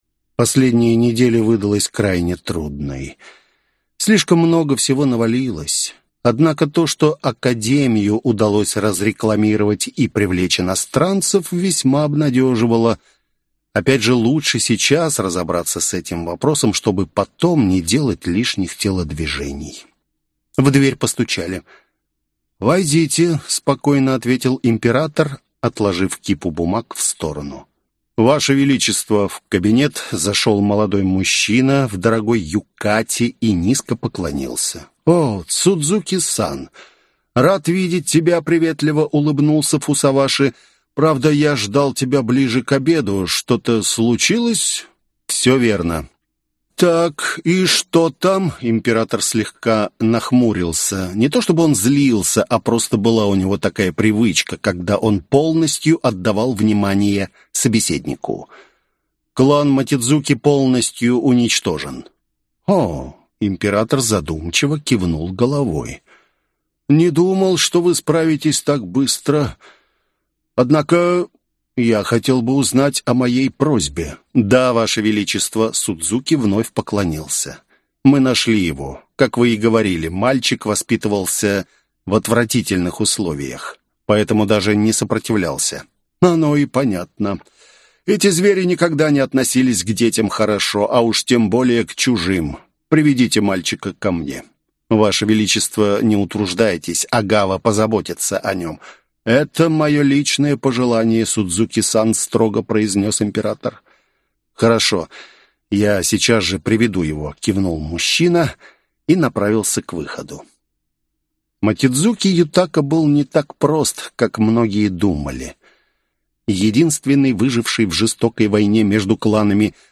Аудиокнига Горизонт событий. Книга 4. Квазар | Библиотека аудиокниг